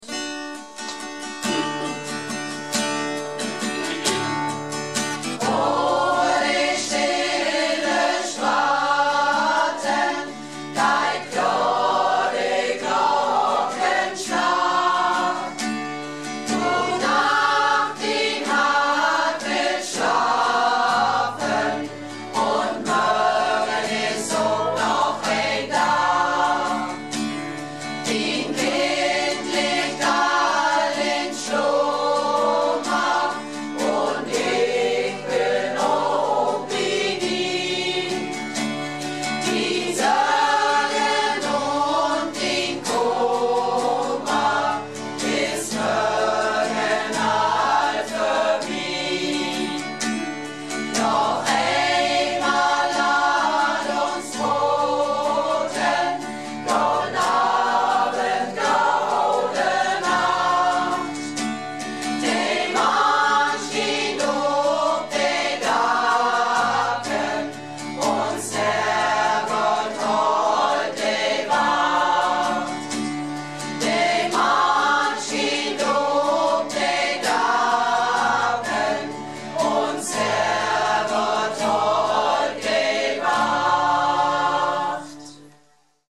Malle Diven - Probe am 18.02.14 in Pewsum